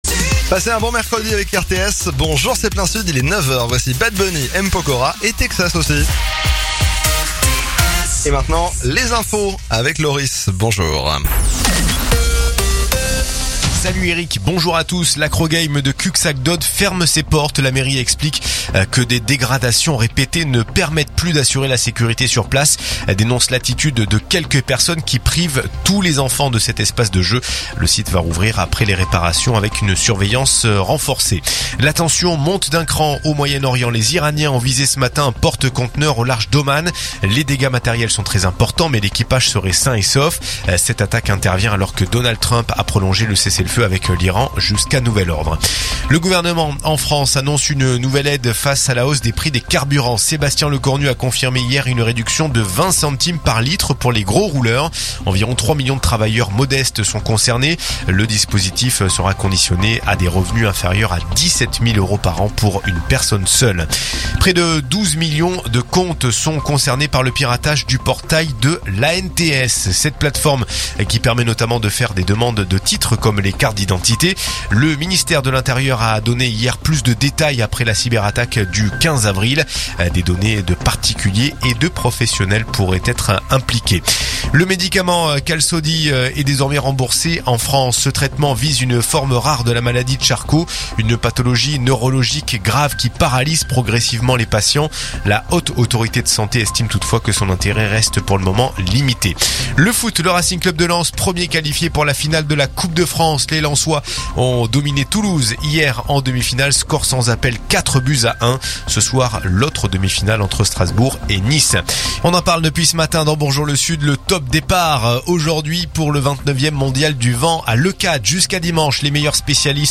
RTS : Réécoutez les flash infos et les différentes chroniques de votre radio⬦
info_narbonne_toulouse_726.mp3